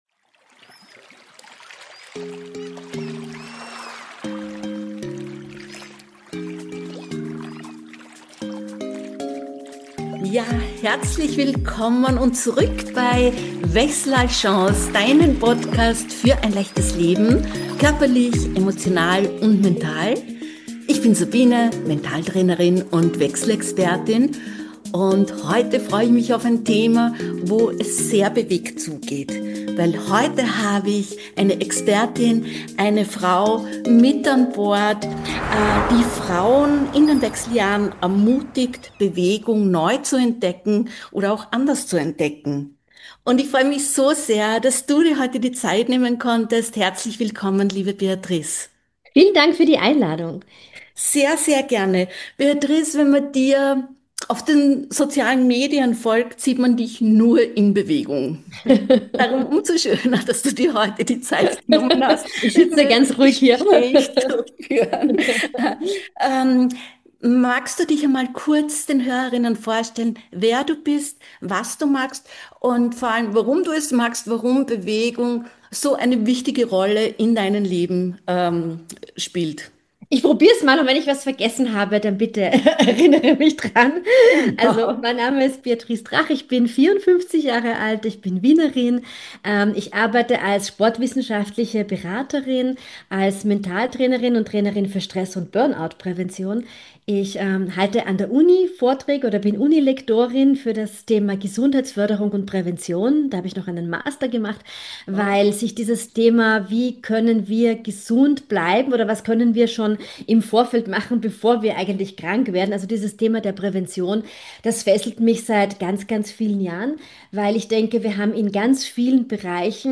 In dieser Episode spreche ich mit einer Fitness-Expertin für Frauen im Wechsel über...